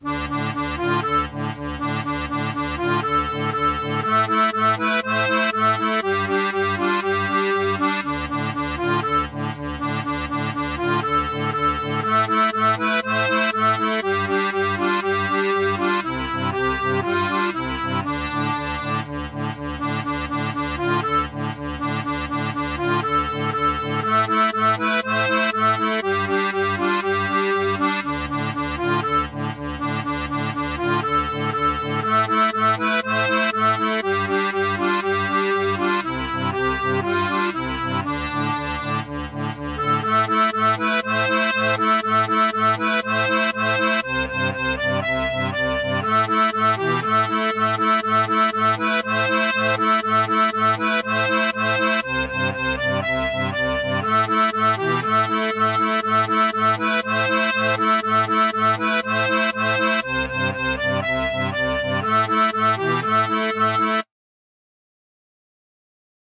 Intermédiaire
Chanson française